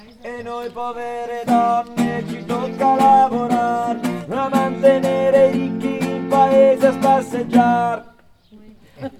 E_anche_per_quest_anno_TENOR.mp3